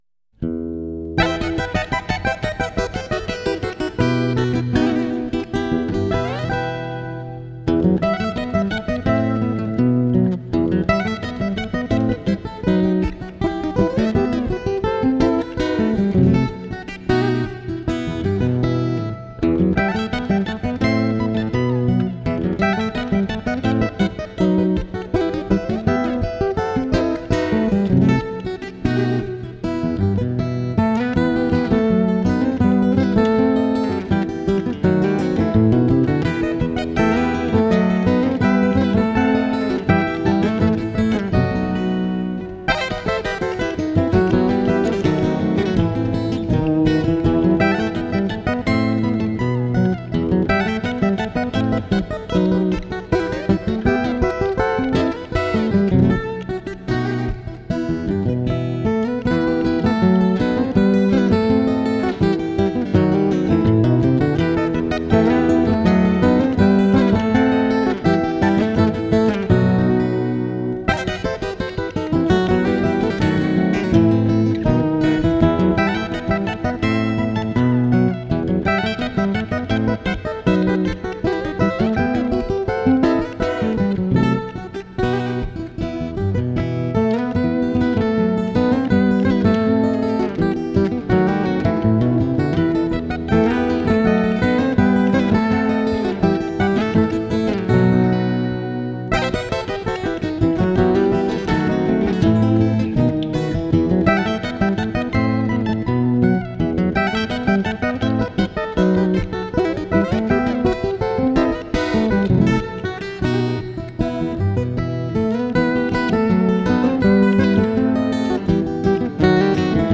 instrumentala